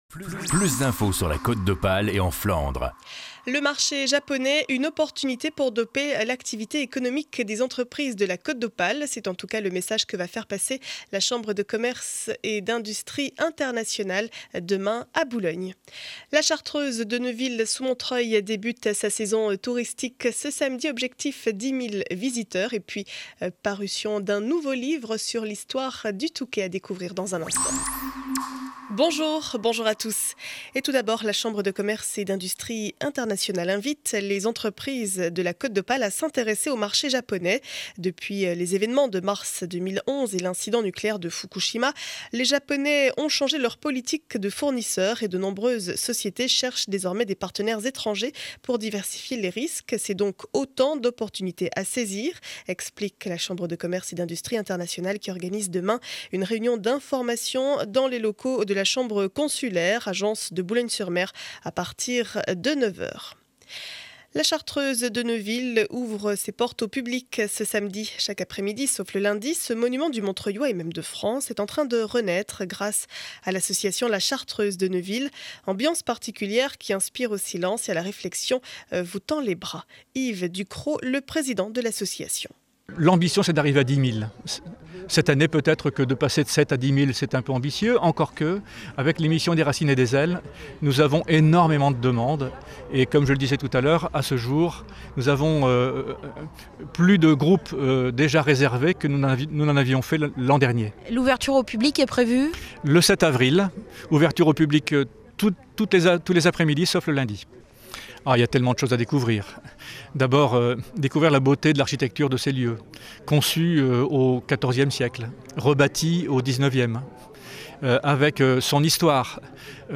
Journal du mardi 03 avril 2012 7 heures 30 édition du Montreuillois.